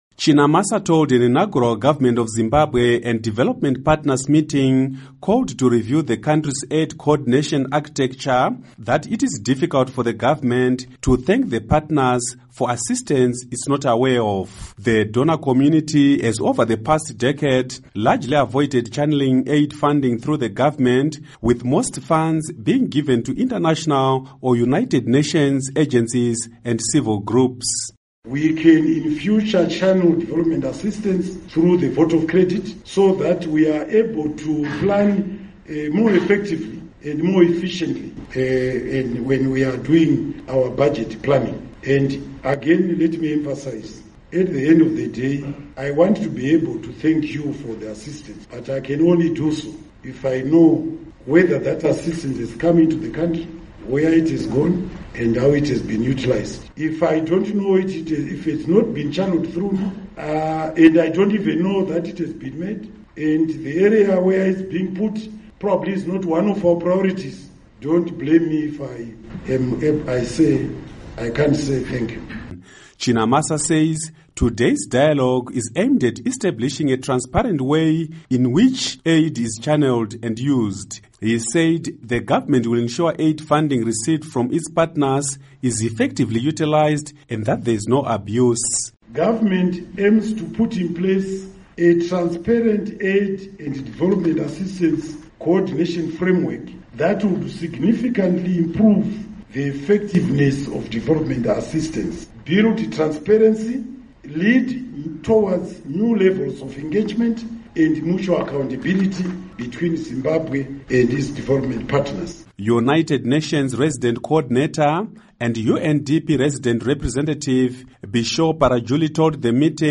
Report on Zimbabwe Donor Funding